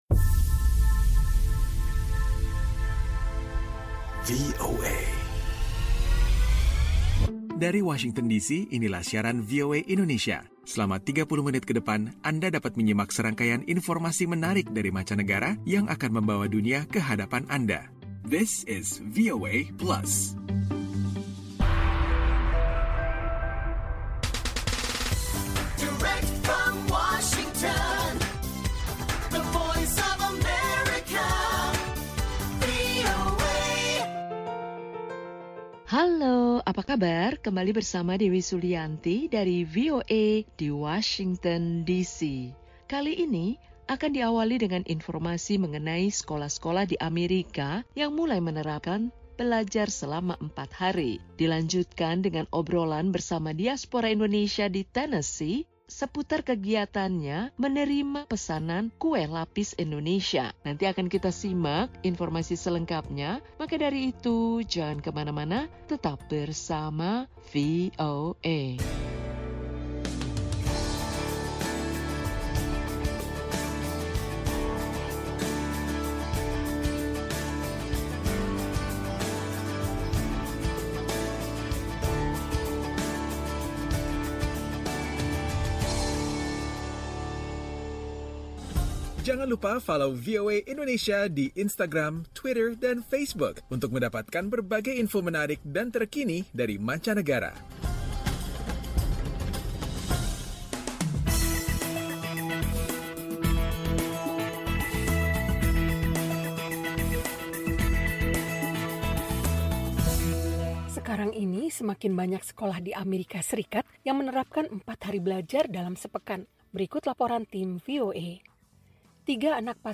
VOA Plus kali ini akan mengajak anda menyimak obrolan bersama seorang diaspora Indonesia di negara bagian Tennessee, pemilik usaha online menjual aneka jenis kue khas Indonesia. Adapula info tentang sejumlah sekolah di Amerika yang mulai merubah jadwal belajar menjadi 4 hari dalam satu minggu.